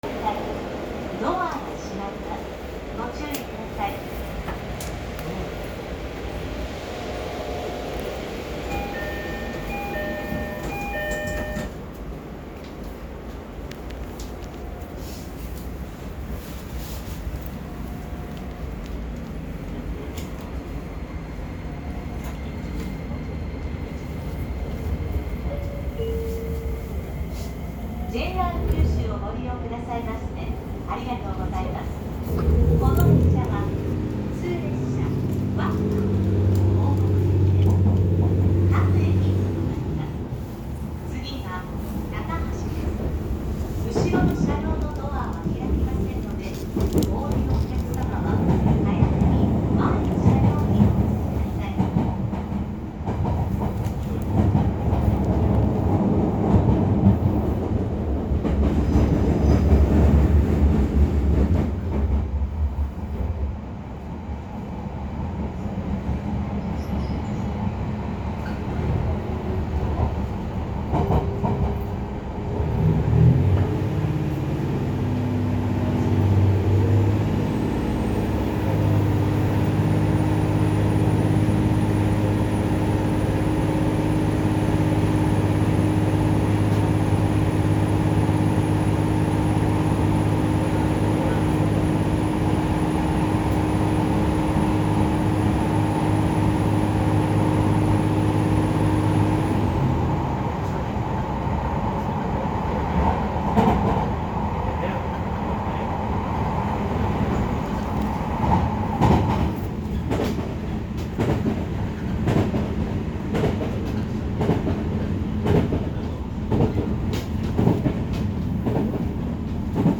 YC1系走行音
【佐世保線】武雄温泉→高橋
ディーゼルエンジンで発電機を動かして走行する…というシステム故、発車の瞬間は電車のような音、程なくディーゼルカーの音、減速＆停車時はモーター音は目立たず…と言った形になります。モーターは日立のSiC適用VVVFとのこと。
それよりも、車内放送の声とドアチャイムのせいで、これだけ異端な車両なのにJR東感が強くてそこに少し違和感を覚えたりします。